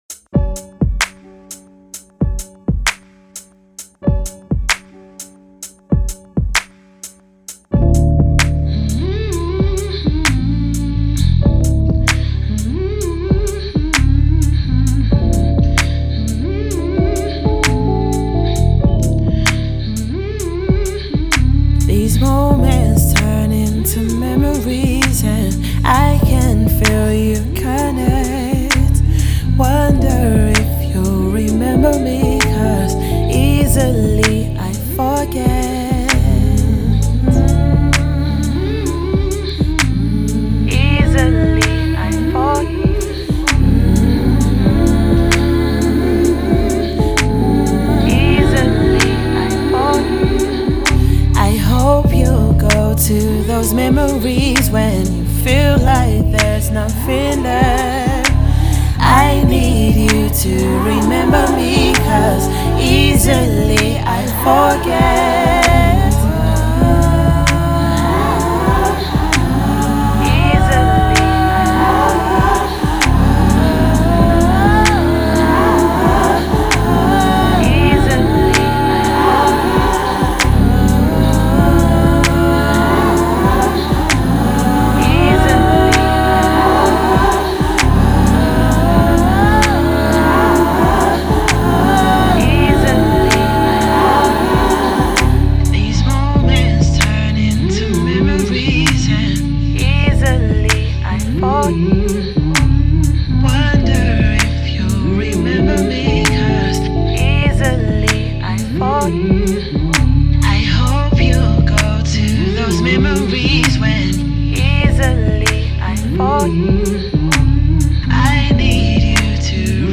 It sounds like London.